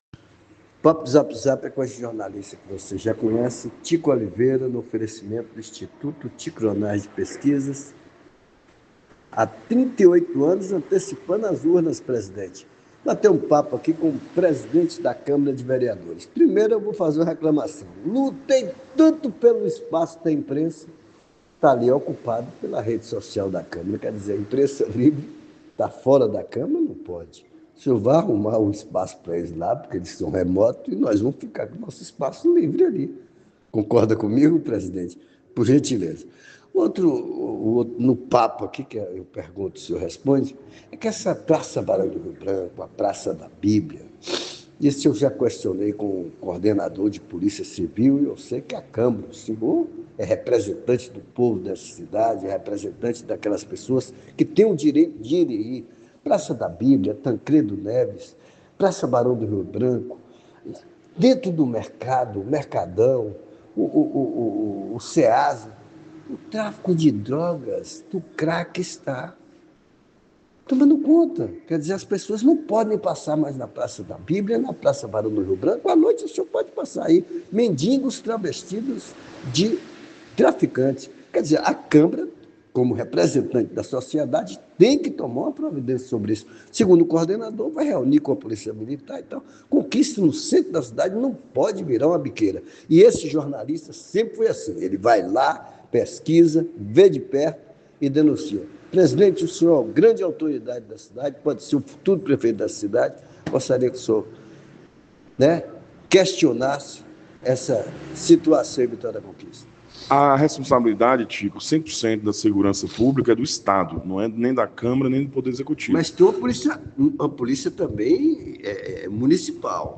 O presidente da Câmara Ivan Cordeiro garantiu que vai autorizar uma comissão da Casa do Povo para fiscalizar essa demanda que está se tornando um verdadeiro perigo para o futuro, garantia e sustentabilidade para as crianças. Ouça o papo do Zap Zap com o presidente da Câmara Ivan Cordeiro e com o coordenador de polícia civil do sudoeste da Bahia Dr. Fabiano Aurich.